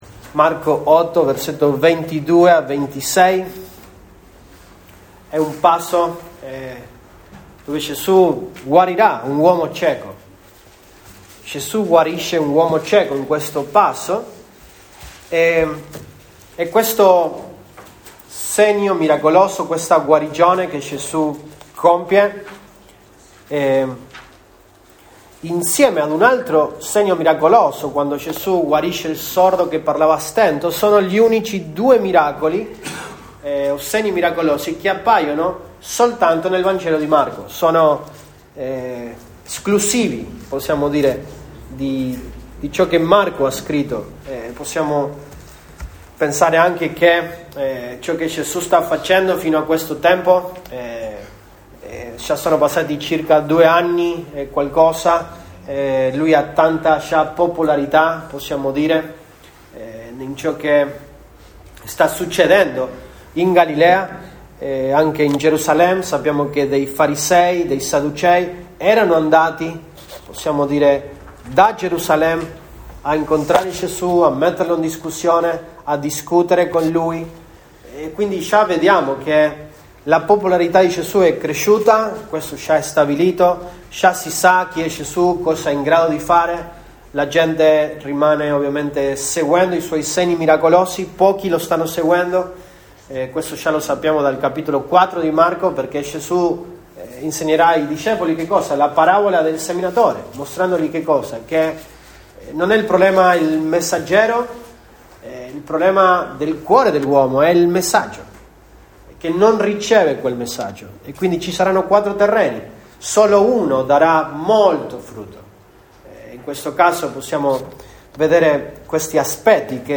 Mag 05, 2024 Gesù Cristo guarisce un uomo cieco MP3 Note Sermoni in questa serie Gesù Cristo guarisce un uomo cieco.